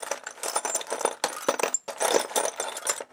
SFX_Metal Sounds_07.wav